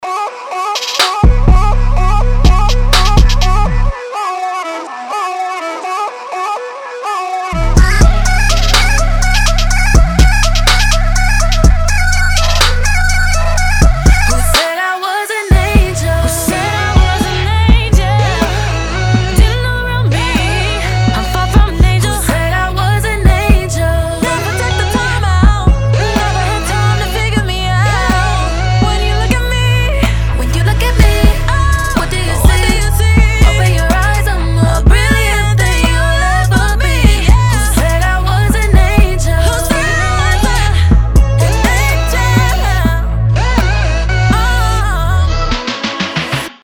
• Качество: 320, Stereo
поп
женский вокал
спокойные
RnB
Bass